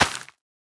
minecraft / sounds / item / plant / crop1.ogg